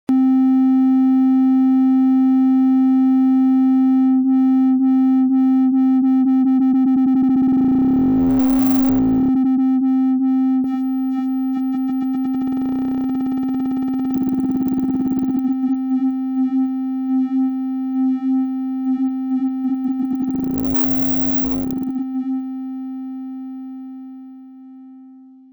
The LFO structure features 6 shapes (sine, triangle, square, saw, noise and random chaos) relative frequency can be synched to clock at different time dividers
demo HEAR LFO